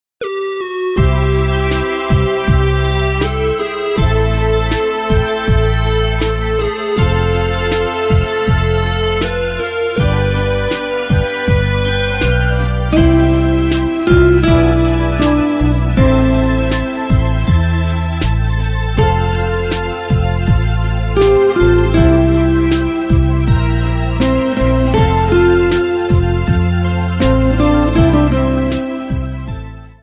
guitar melodies from Poland